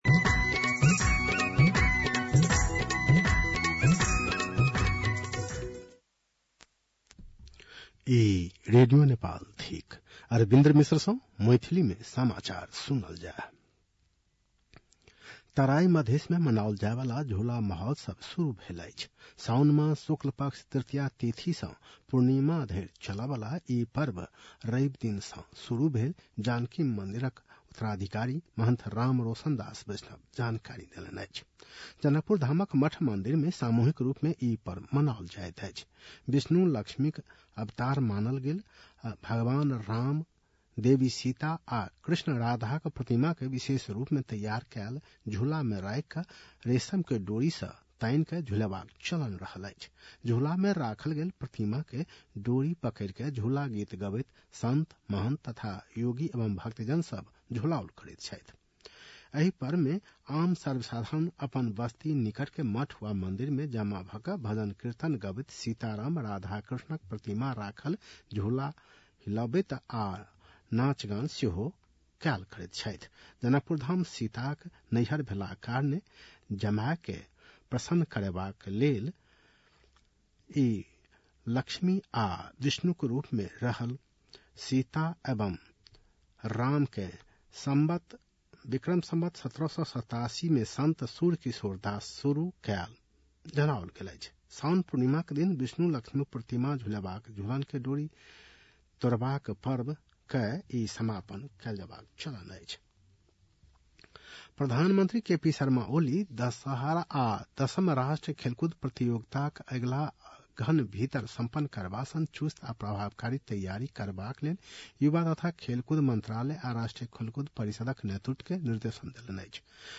मैथिली भाषामा समाचार : १२ साउन , २०८२
6.-pm-maithali-news-1-4.mp3